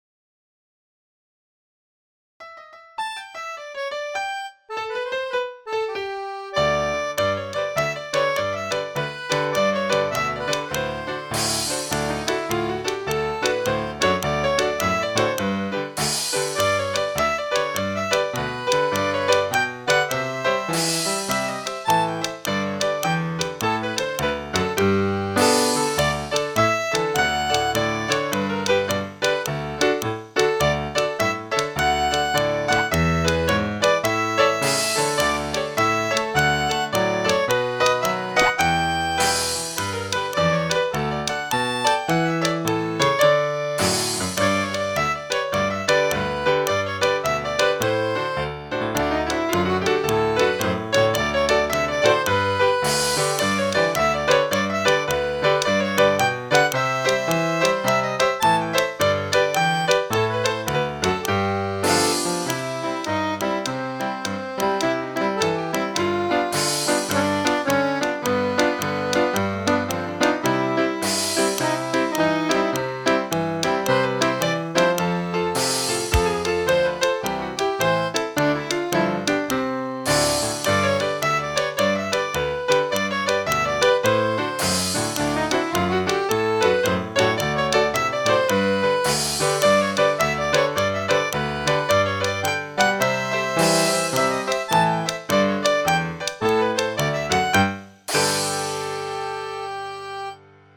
The Ceilidh Sets